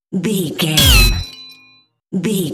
Dramatic hit metal electricity debris
Sound Effects
heavy
intense
dark
aggressive